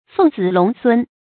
鳳子龍孫 注音： ㄈㄥˋ ㄗㄧˇ ㄌㄨㄙˊ ㄙㄨㄣ 讀音讀法： 意思解釋： 帝王或貴族的后代。